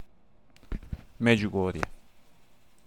Medjugorje[note 1] (Serbo-Croatian: Međugorje, pronounced [mêdʑuɡoːrje]
Hr-Međugorje.ogg.mp3